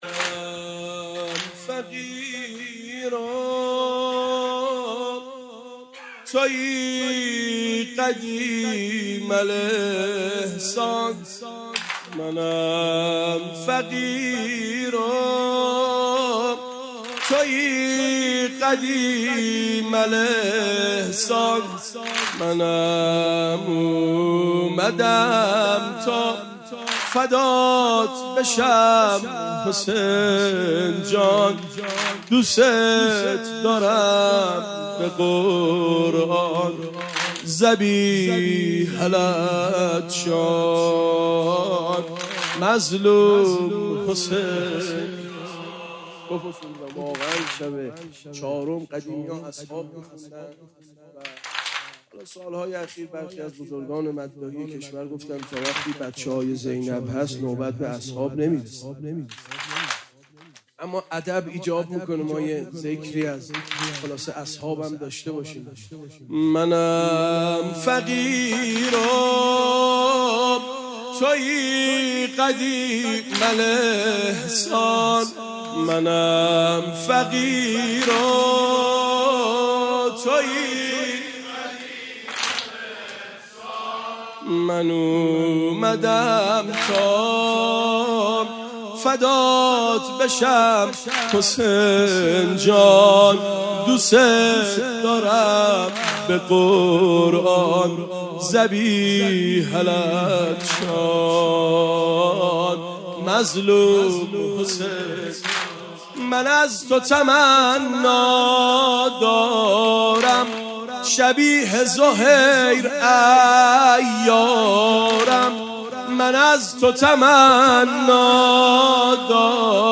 شب چهارم محرم